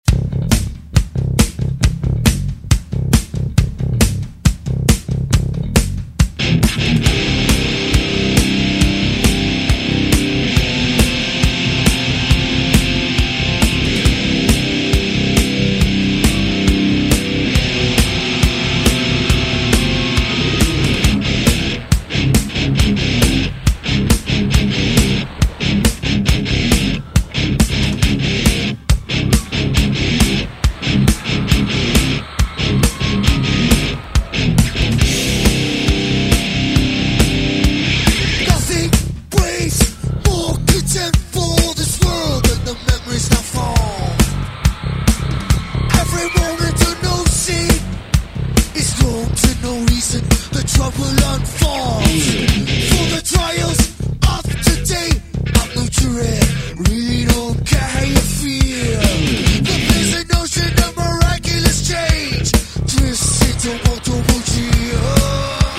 • Качество: 128, Stereo
мужской голос
громкие
Драйвовые
электрогитара
groove metal
Жесткий метал